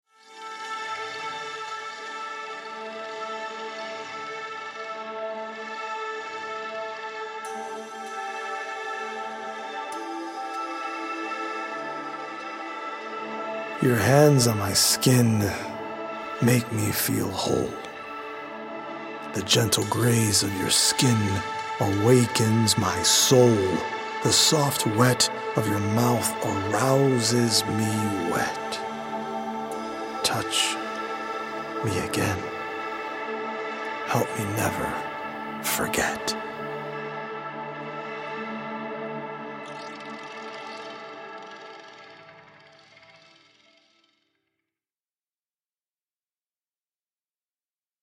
healing Solfeggio frequency music